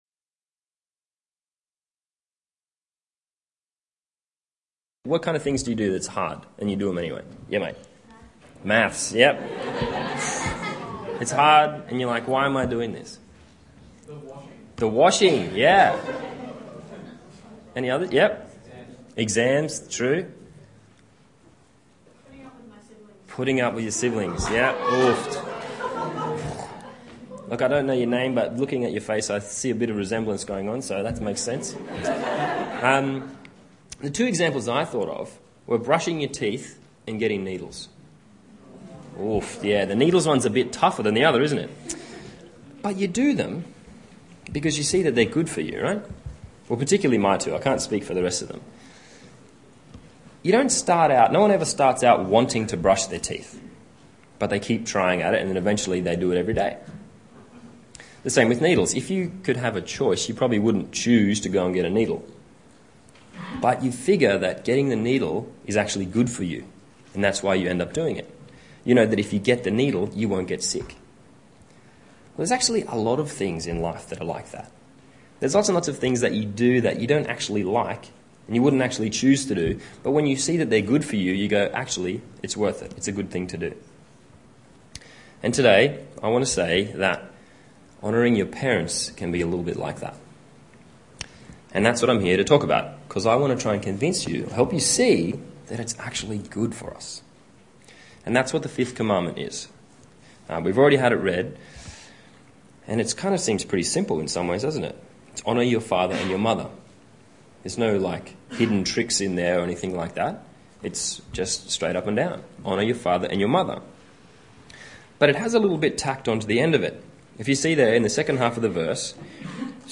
Youth Church